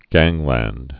(gănglănd)